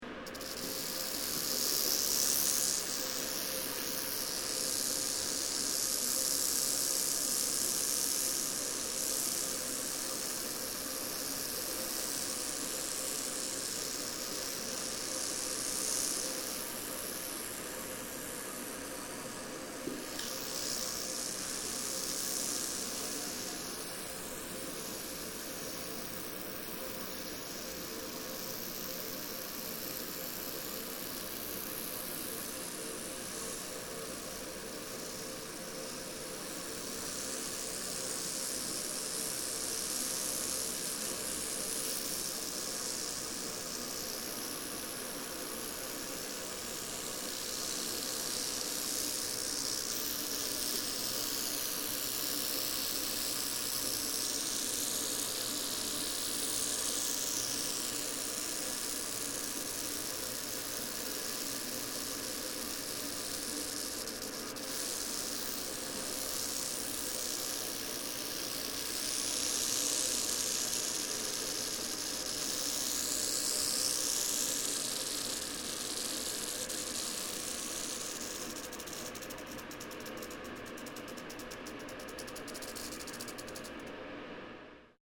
Indoor recording of the rattling of a captive adult Arizona Ridge-nosed Rattlesnake (courtesy of the Arizona-Sonora Desert Museum.) This species has small rattles which make a relatively quiet buzzing sound.